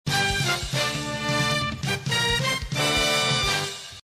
Play, download and share round win original sound button!!!!
round-win_DROZFGx.mp3